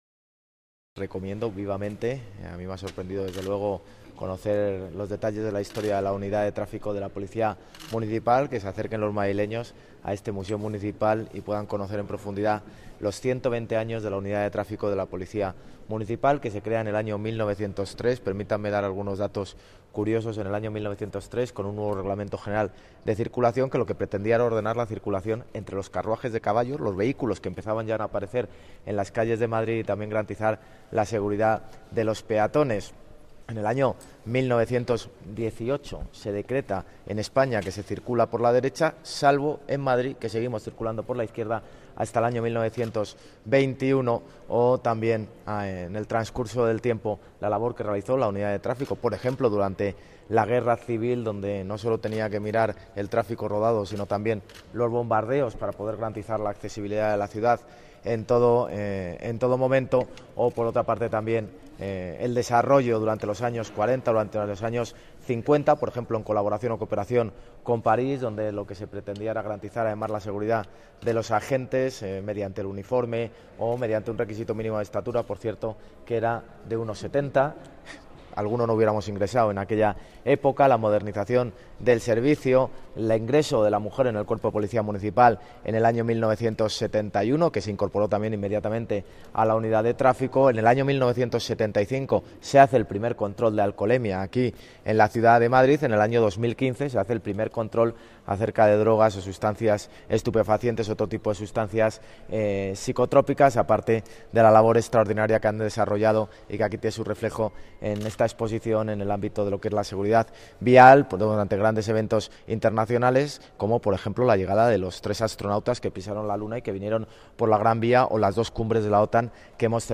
Nueva ventana:Declaraciones del alcalde de Madrid, José Luis Martínez-Almeida